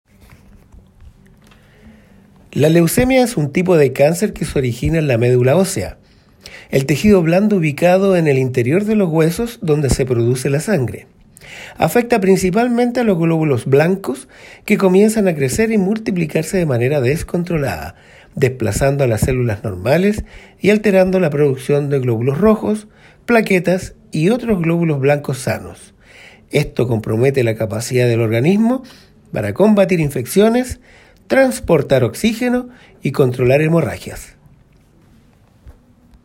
El seremi de Salud (s) de Atacama, Richard González, explicó que “la leucemia es un tipo de cáncer que se origina en la médula ósea, el tejido blando ubicado en el interior de los huesos donde se produce la sangre.